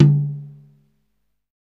• Long Tom Drum One Shot D Key 16.wav
Royality free tom drum sound tuned to the D note. Loudest frequency: 236Hz
long-tom-drum-one-shot-d-key-16-25p.wav